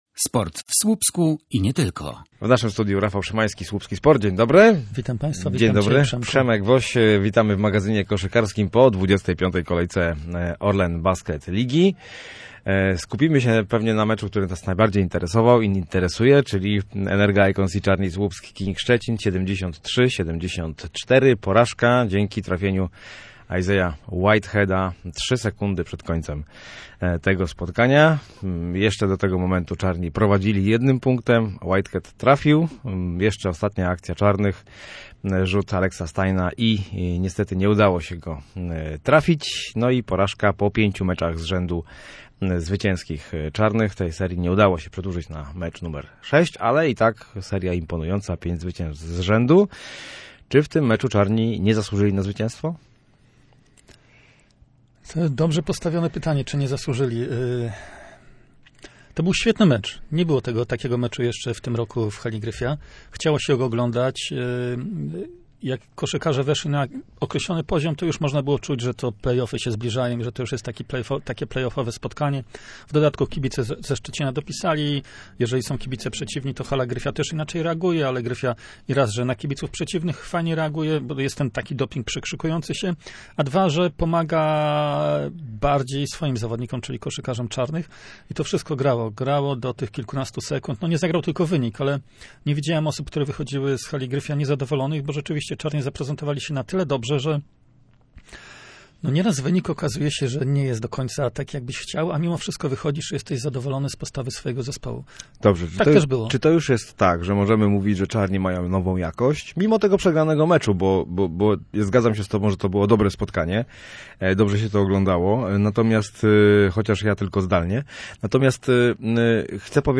Magazyn_Koszykarski_OK-1.mp3